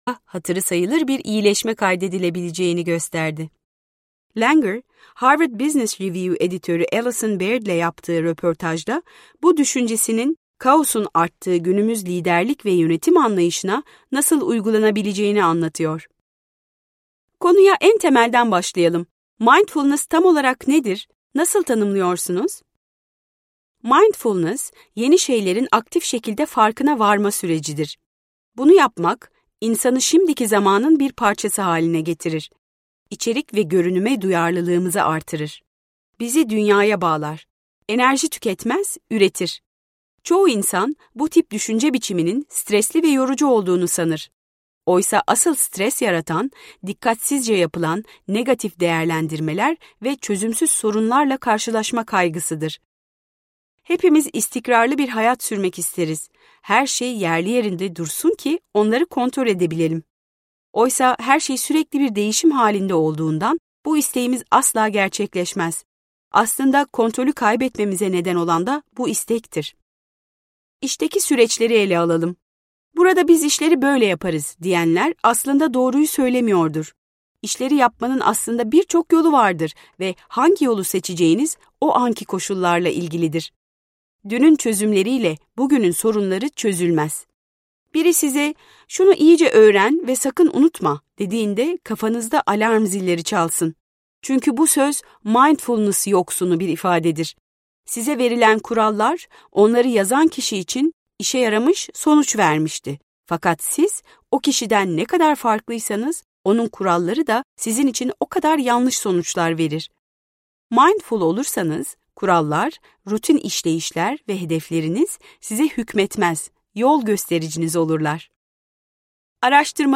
Mindfulness - Seslenen Kitap